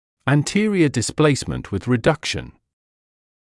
[æn’tɪərɪə dɪs’pleɪsmənt wɪð rɪ’dʌkʃn][эн’тиэриэ дис’плэйсмэнт уиз ри’дакшн]переднее смещение (диска) с вправлением